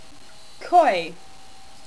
The Koi Pronunciation: Koi - coy